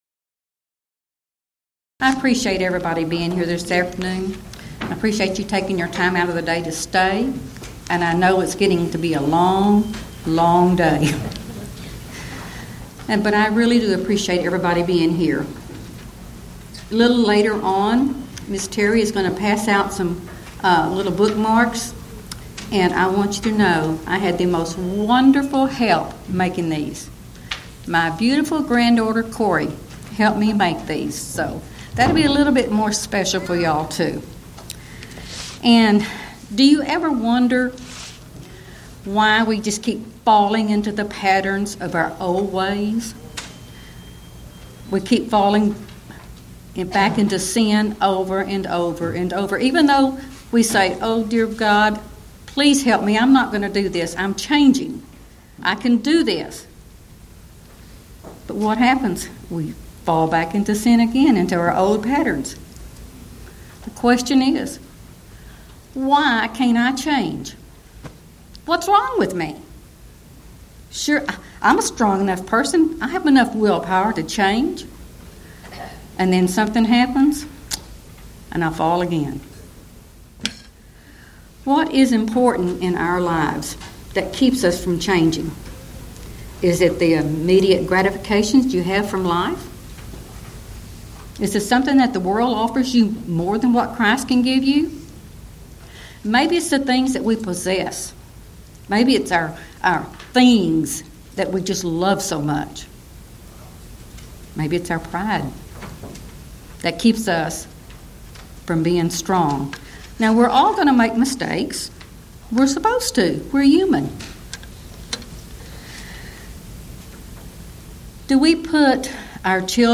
Event: 21st Annual Gulf Coast Lectures
Ladies Sessions